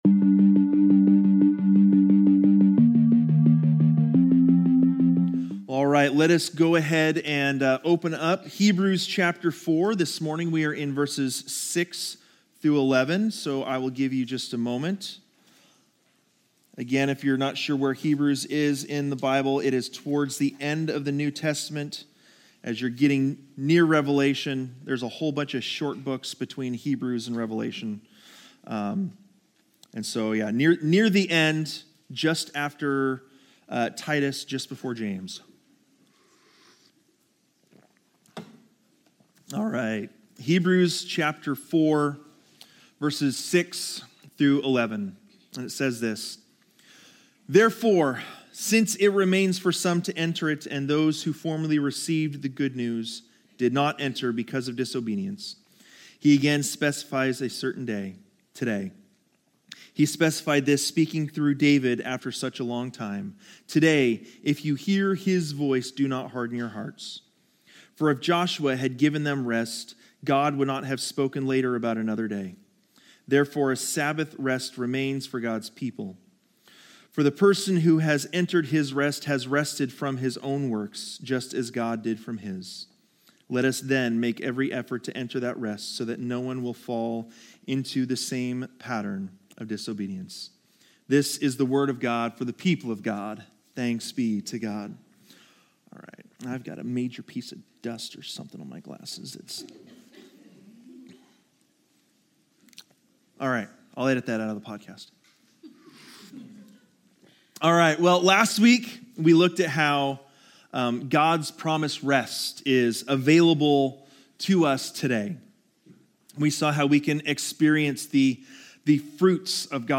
Learn how the "good news" transforms lives only when received in faith, and discover why community accountability is crucial for maintaining spiritual health. This sermon challenges believers to examine their hearts and make every effort to enter God's rest while warning against falling into patterns of disobedience.